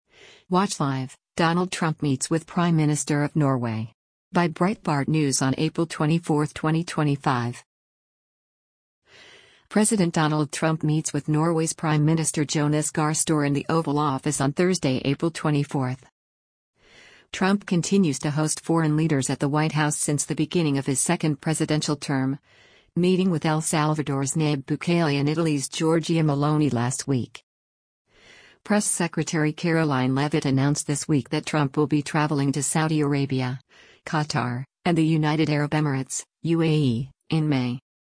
President Donald Trump meets with Norway’s Prime Minister Jonas Gahr Støre in the Oval Office on Thursday, April 24.